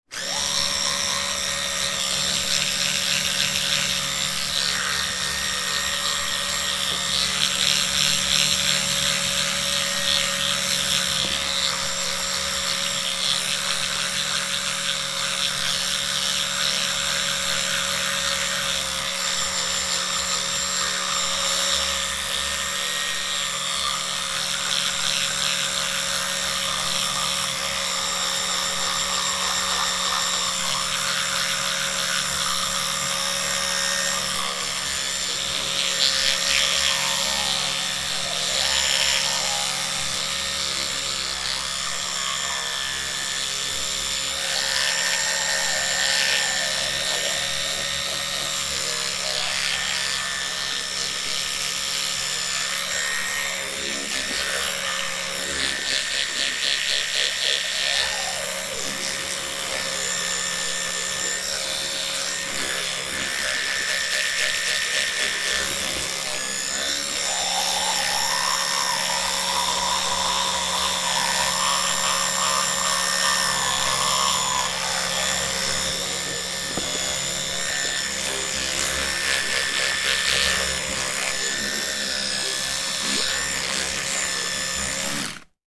Звуки чистки зубов